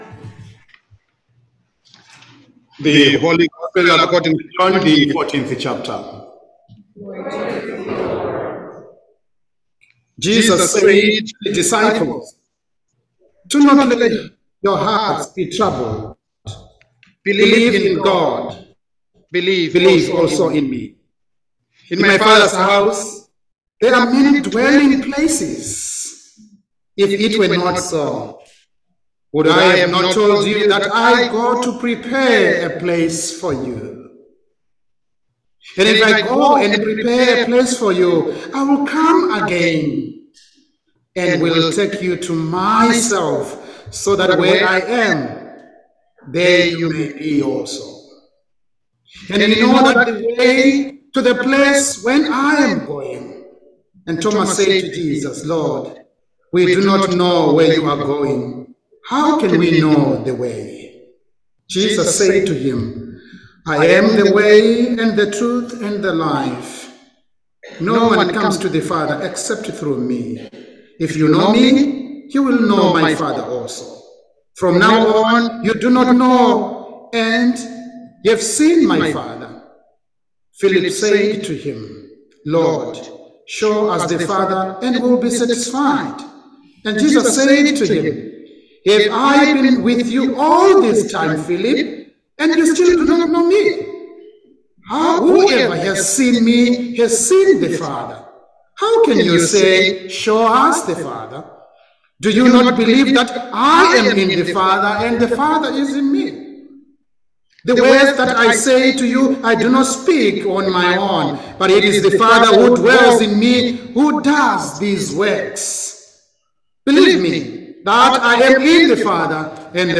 Sermon
Room Enough: Sermon for the Fifth Sunday of Easter 2023